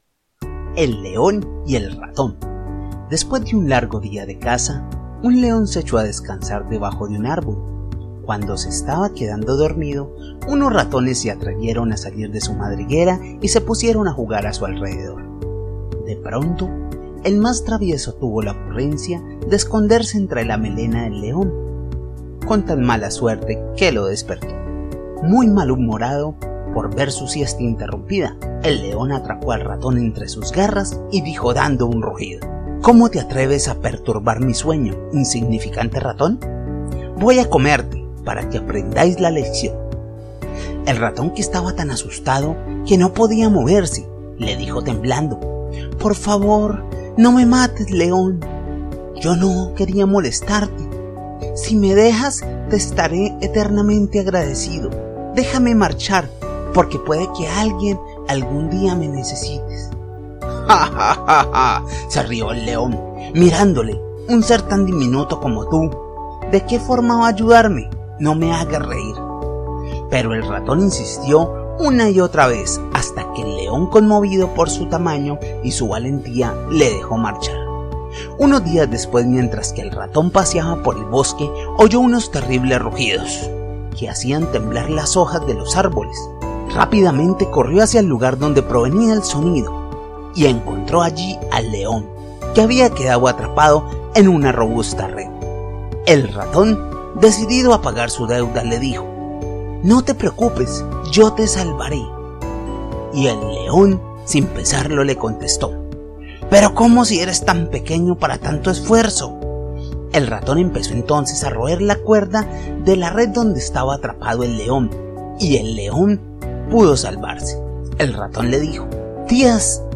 spanisch Südamerika
kolumbianisch
Sprechprobe: eLearning (Muttersprache):
E-LEARNING.mp3